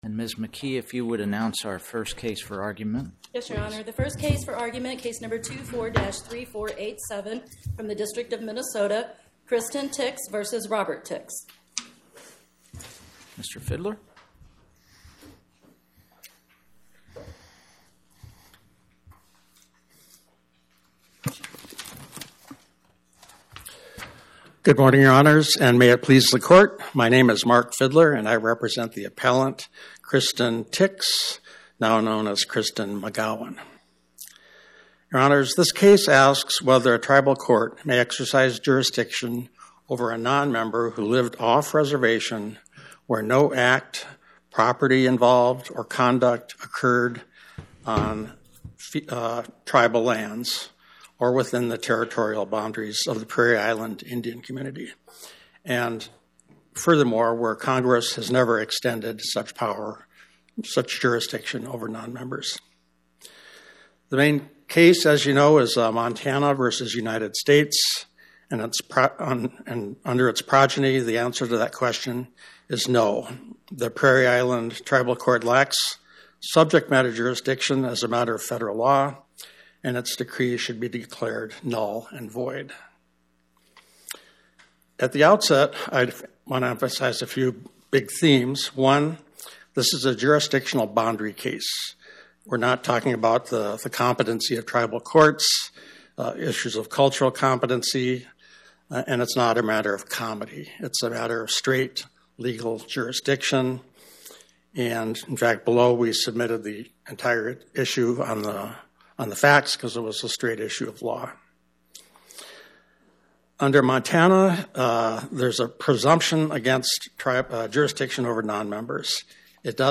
Oral argument argued before the Eighth Circuit U.S. Court of Appeals on or about 10/21/2025